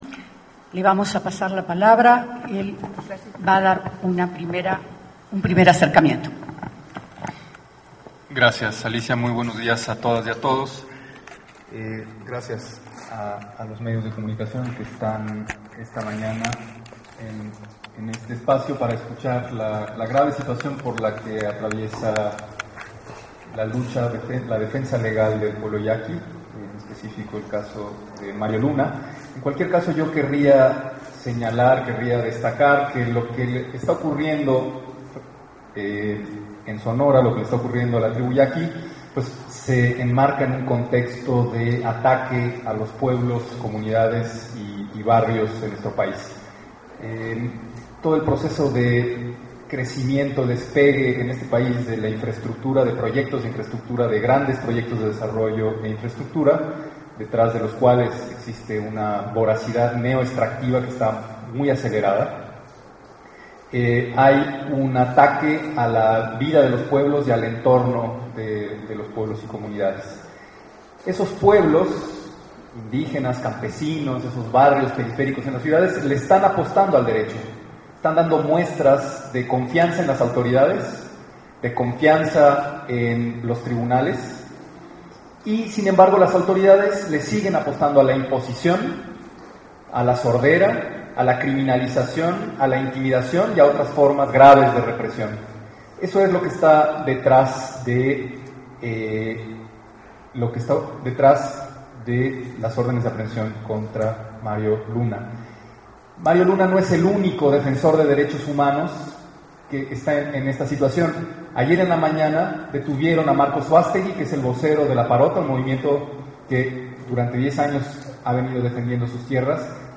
En conclusión los conferencistas concuerdan con  tres puntos:  en México las obras se realizan sin estudios previos de impacto ambiental, se desacata las sentencias de la SCJN y la nueva ley de amparo no funciona debidamente.